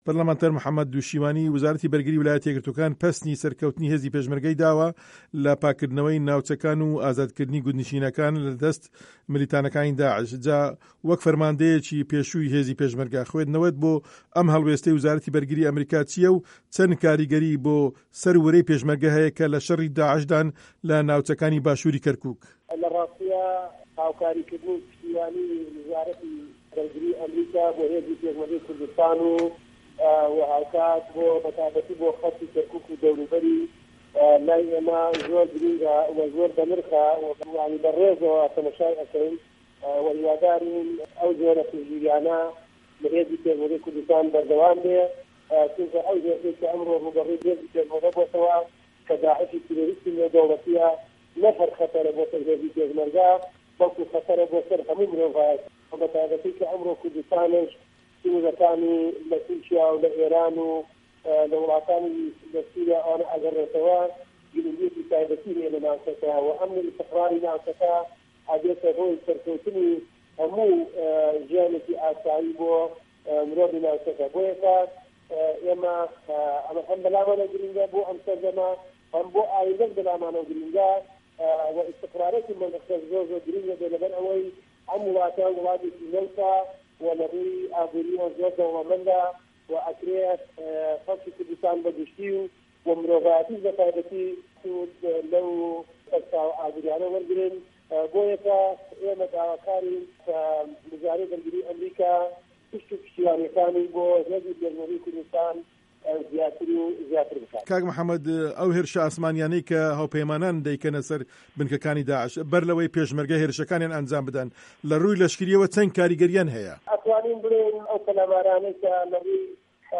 وتووێژ لەگەڵ محەمەد دوشیوانی